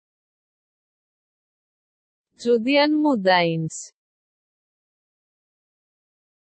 Judean Mountains (ޖޫޑިއަން މައުންޓެއިންސް) އަށް ގްރީކް ބަހުން ކިޔަނީ “ޖޫދިއަން މޮދާއިސް” އެވެ.